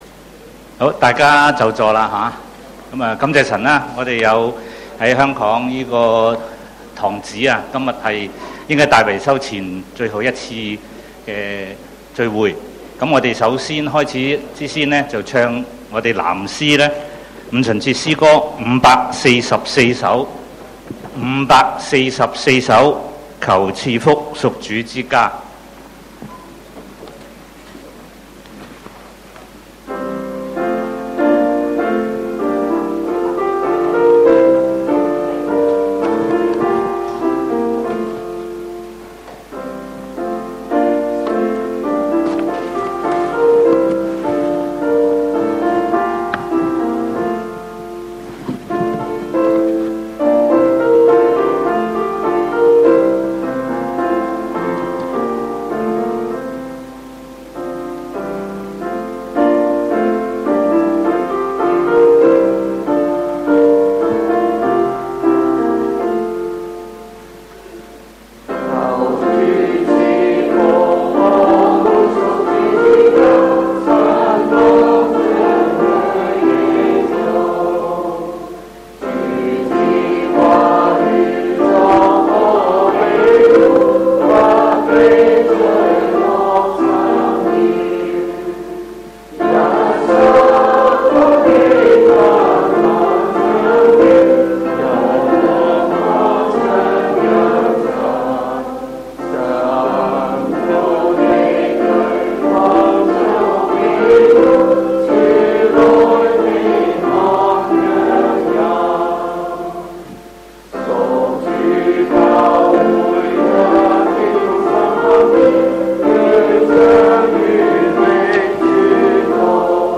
見証會 – 港九五旬節會